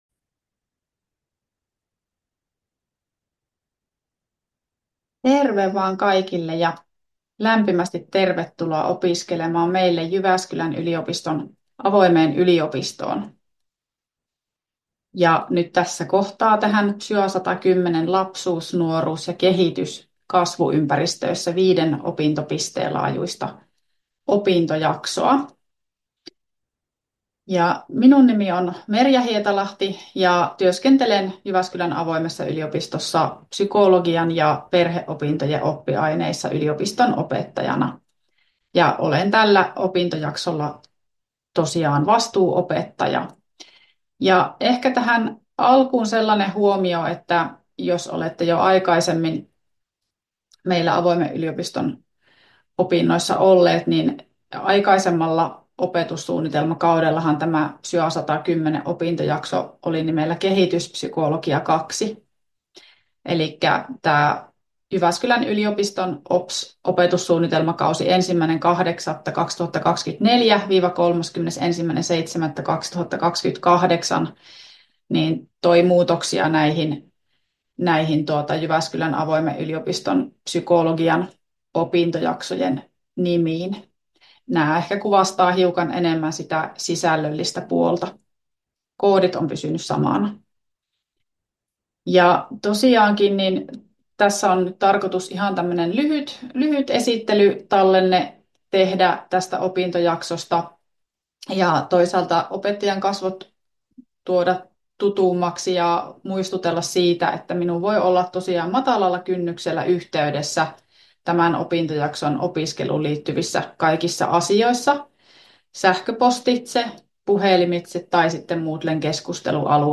PSYA110 johdantoluento 2025-2028 — Moniviestin